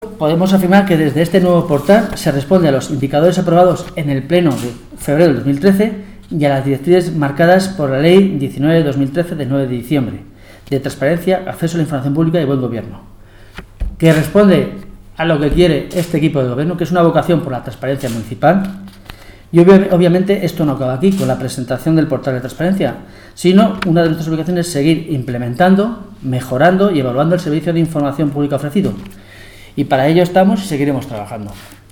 Declaraciones del concejal sobre el portal transparencia del Ayuntamiento